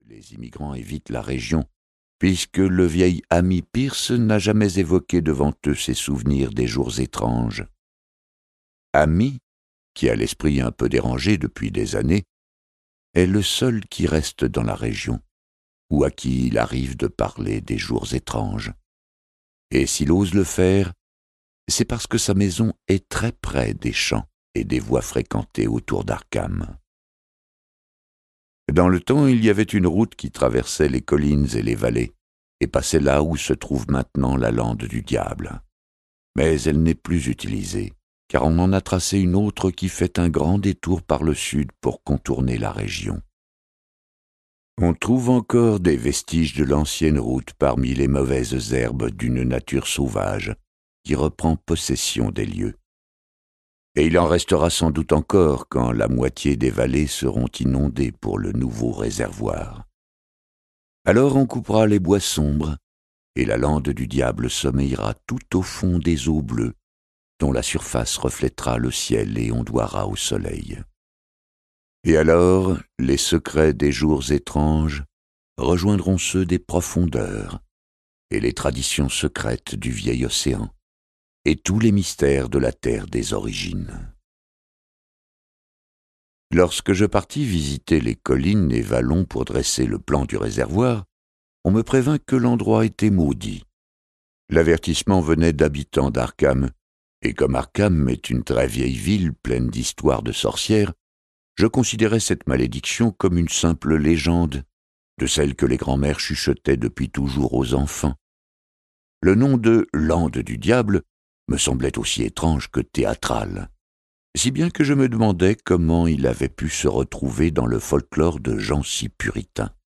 Le mythe de Cthulhu n'a jamais été aussi réel…Ce livre audio est interprété par une voix humaine, dans le respect des engagements d'Hardigan.